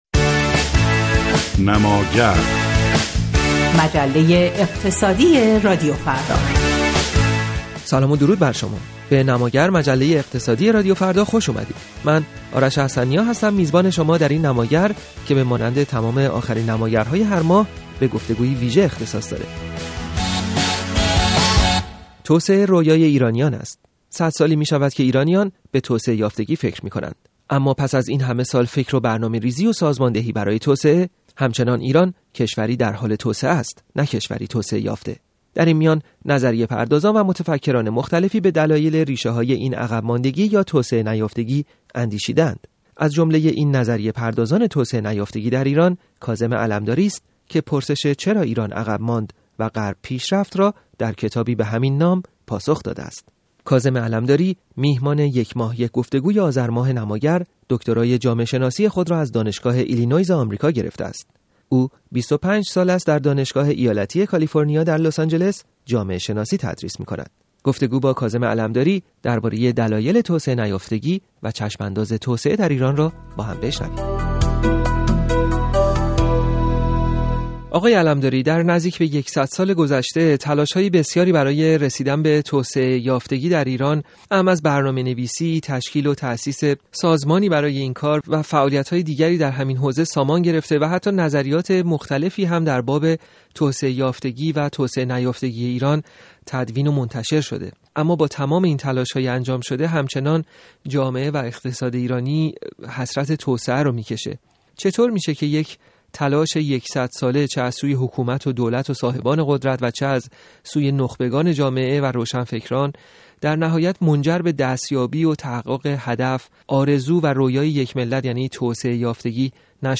در گفت‌وگو